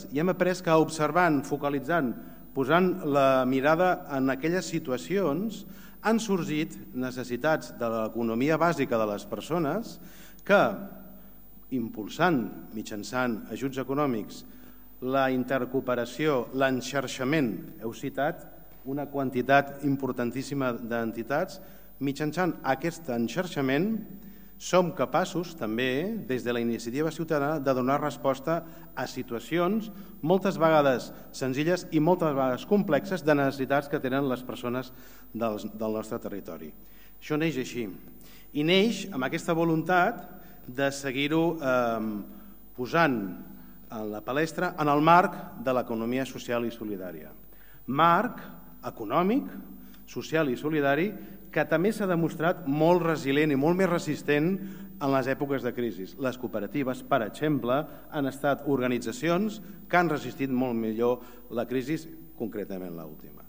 Tall de veu de l'alcalde, Miquel Pueyo, sobre La Descomunal Lleida (693.7 KB) Tall de veu del secretari de Treball del Departament d’Empresa i Treball, Enric Vinaixa, sobre la Descomunal Lleida (615.3 KB)
tall-de-veu-del-secretari-de-treball-del-departament-d2019empresa-i-treball-enric-vinaixa-sobre-la-descomunal-lleida